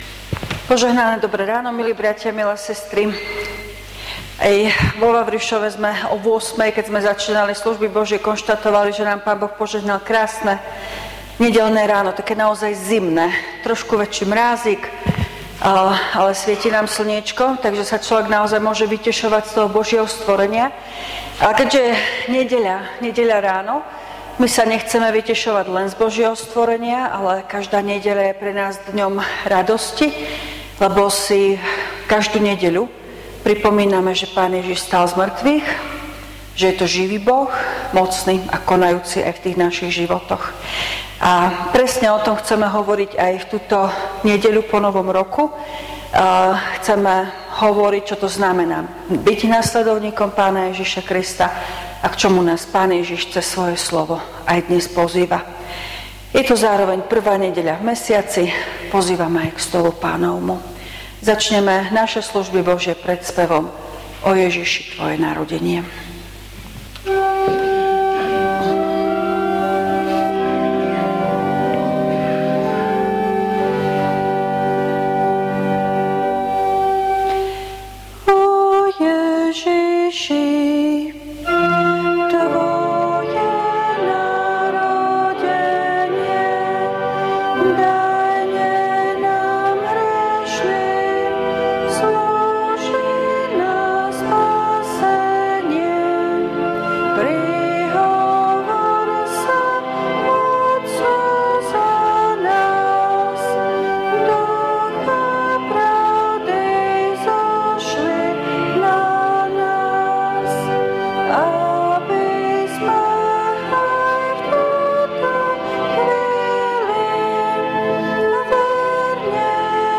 Služby Božie – Nedeľa po Novom roku
V nasledovnom článku si môžete vypočuť zvukový záznam zo služieb Božích – Nedeľa po Novom roku.